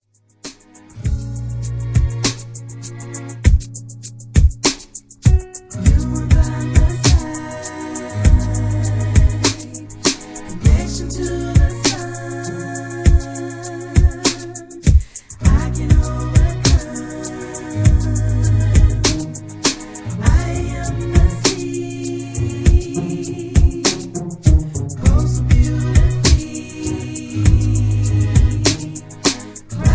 (Key-Gb) Karaoke MP3 Backing Tracks
Just Plain & Simply "GREAT MUSIC" (No Lyrics).